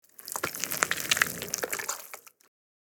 Download Free Blood Sound Effects | Gfx Sounds
Guts-and-blood-squeezing-slosh-4.mp3